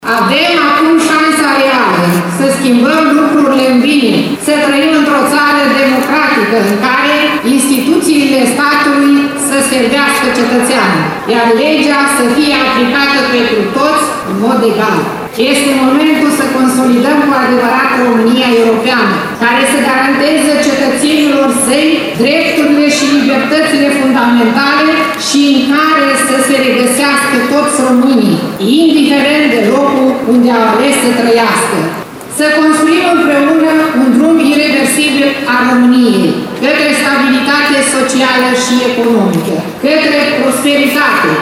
După intonarea imnului național și oficierea unui serviciu religios de către arhiepiscopul Sucevei și Rădăuților PIMEN au urmat alocuțiunile oficialităților.
Subprefectul SILVIA BOLIACU.